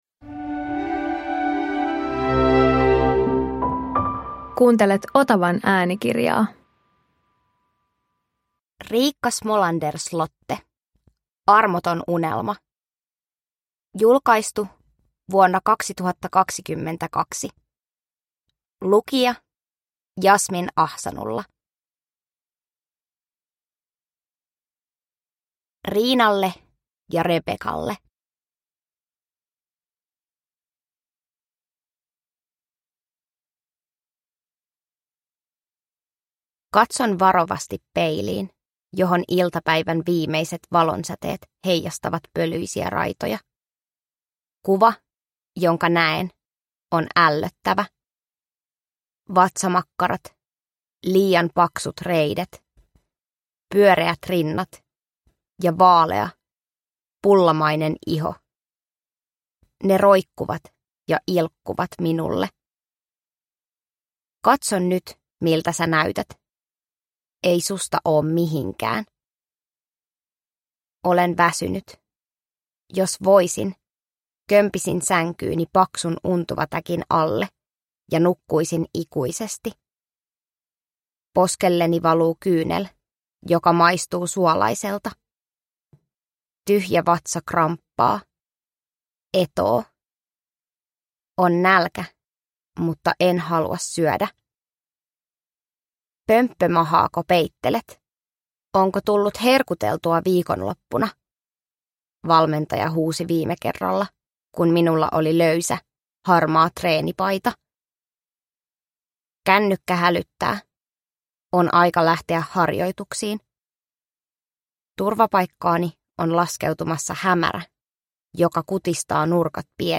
Armoton unelma – Ljudbok – Laddas ner